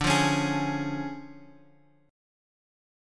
D Minor Major 7th Flat 5th